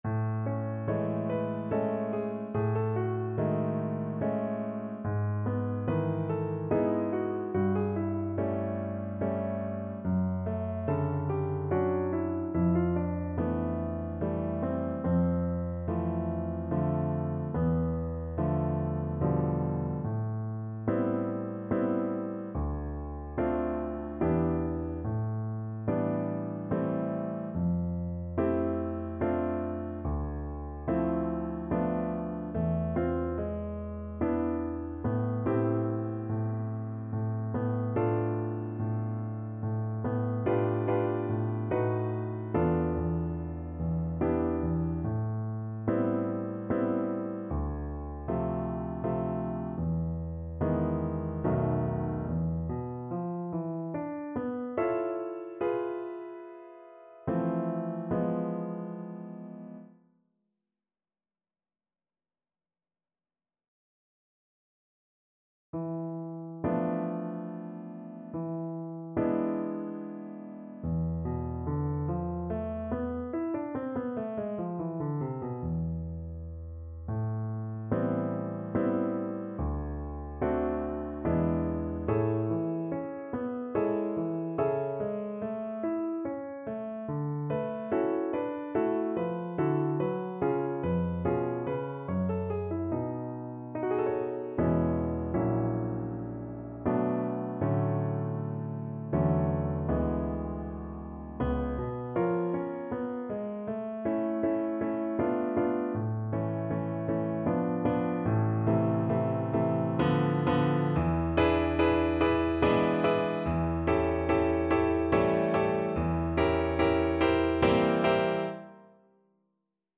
Play (or use space bar on your keyboard) Pause Music Playalong - Piano Accompaniment Playalong Band Accompaniment not yet available transpose reset tempo print settings full screen
A minor (Sounding Pitch) (View more A minor Music for Violin )
3/4 (View more 3/4 Music)
Andante =72